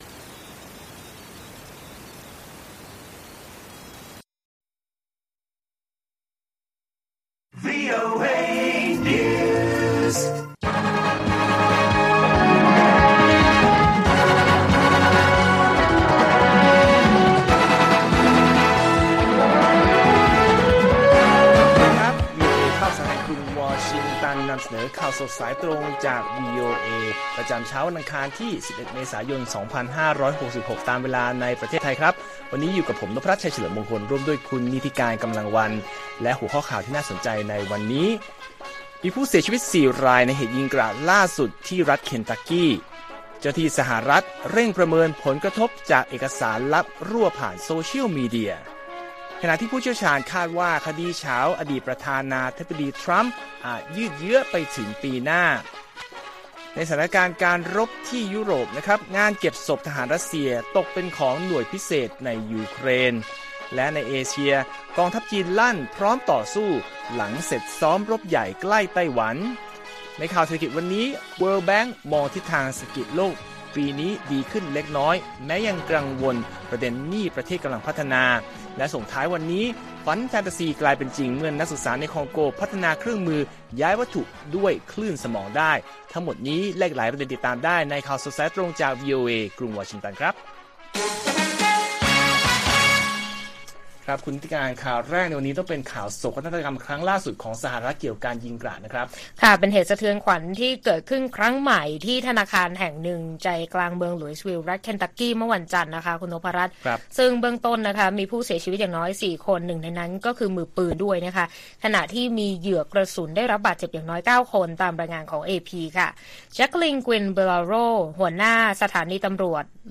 ข่าวสดสายตรงจากวีโอเอ ไทย อังคาร 11 เมษายน 2566